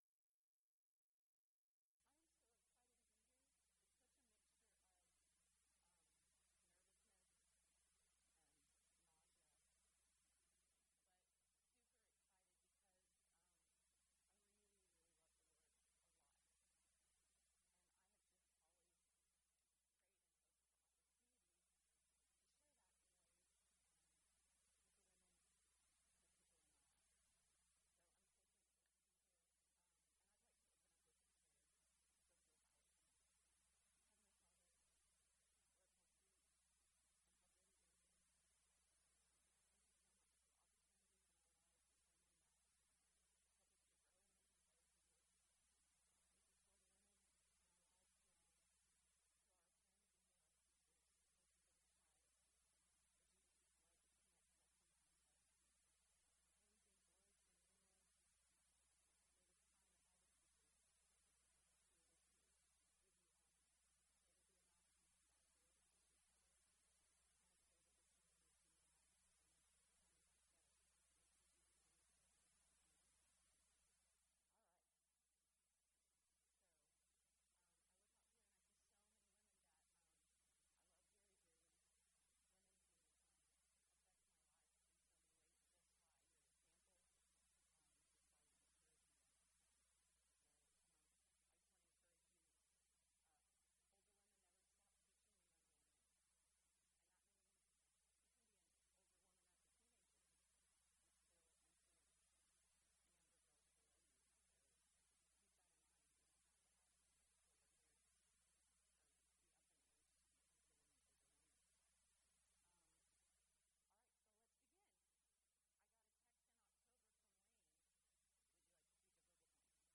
Ladies Sessions